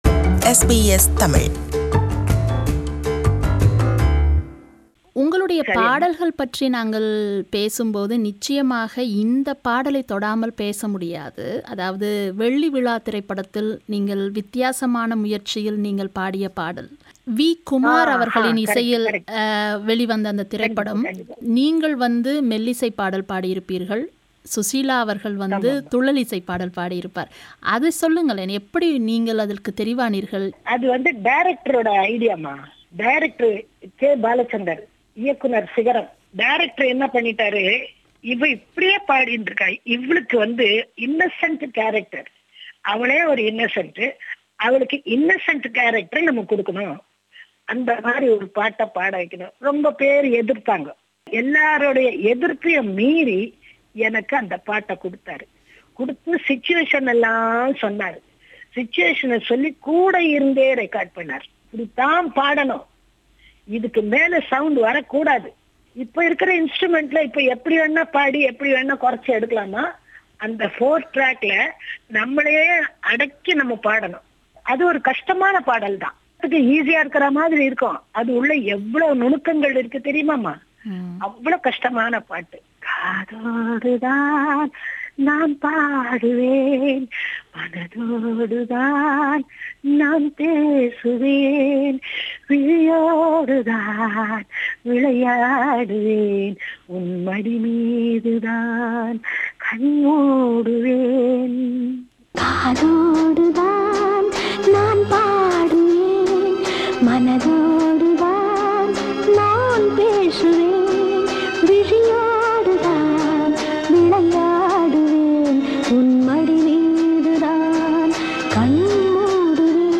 This is part III of her interview